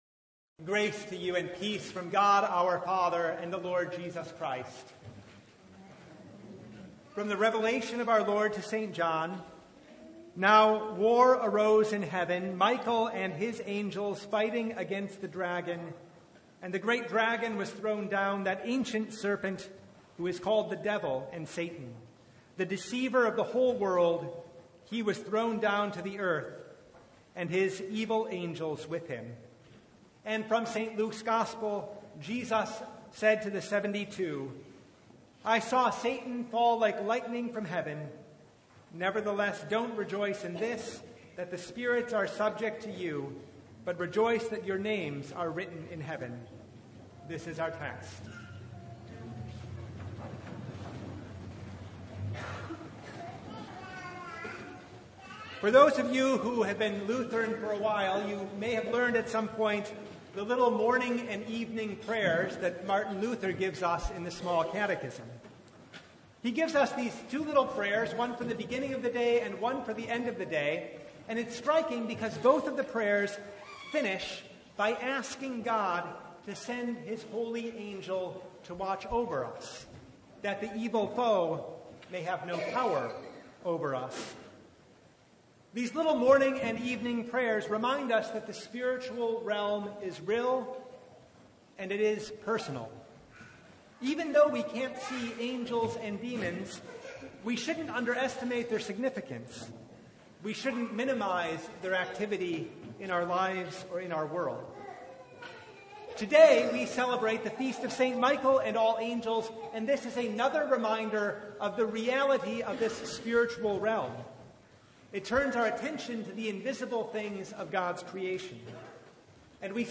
Service Type: Sunday
Download Files Notes Topics: Sermon Only « Grace and Shrewdness Living by Faith in a “How Long?”